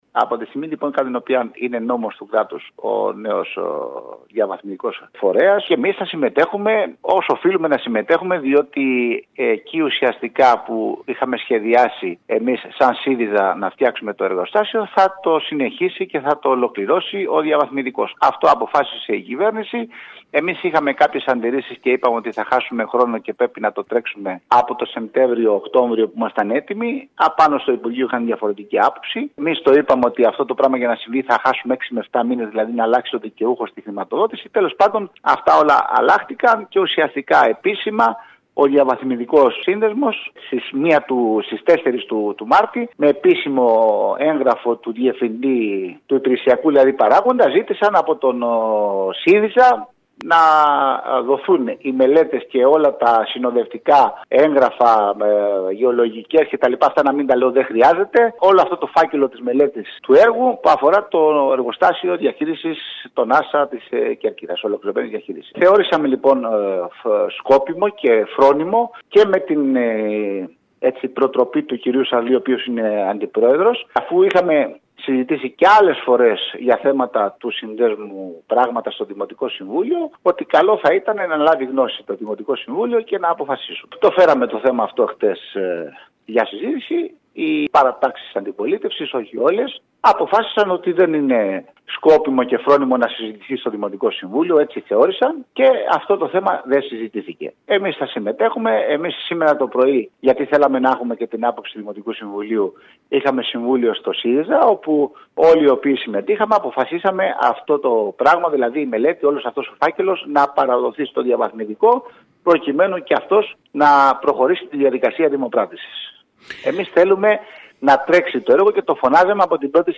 Αυτό δήλωσε, μιλώντας σήμερα στην ΕΡΑ ΚΕΡΚΥΡΑΣ, ο αντιδήμαρχος καθαριότητας Γιάννης Σερεμέτης.